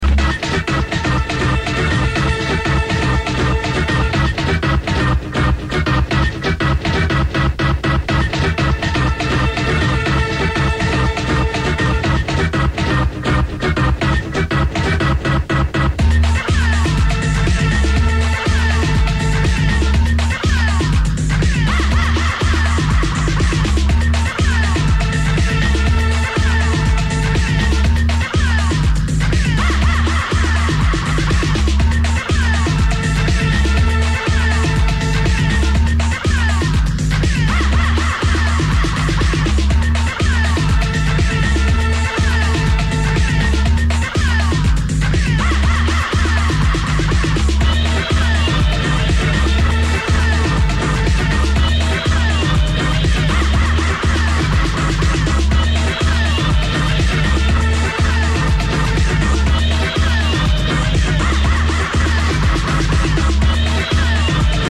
avec son baladeur dans un train